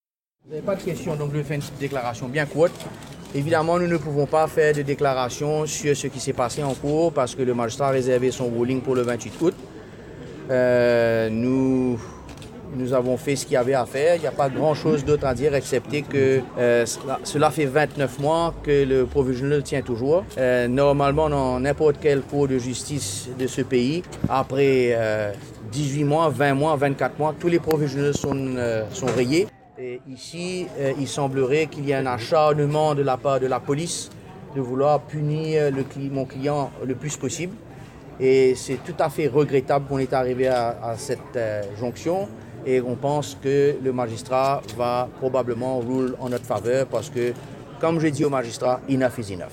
Ecoutez sa déclaration à la sortie du tribunal.